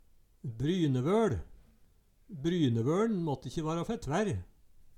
brynevøL - Numedalsmål (en-US)